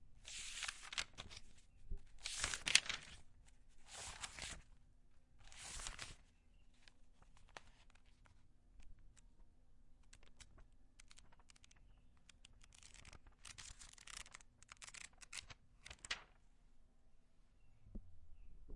描述：翻页报纸。
Tag: 纸张 翻页 旋转 报纸